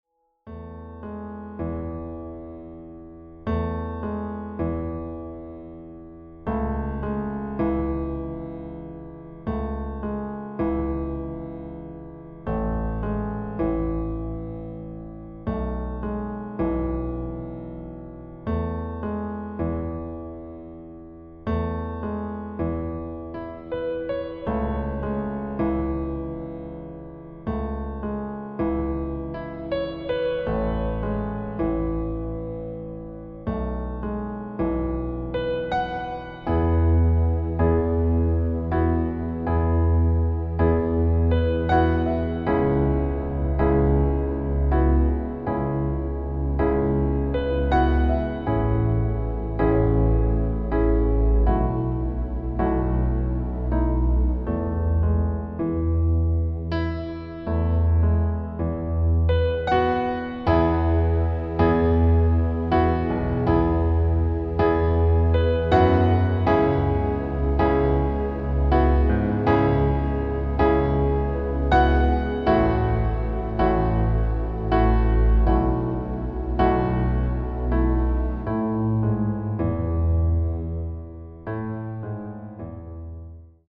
Klavierversion mit Streichern
Das Instrumental beinhaltet NICHT die Leadstimme